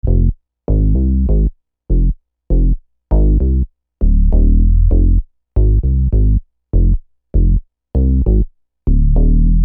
Bass 19.wav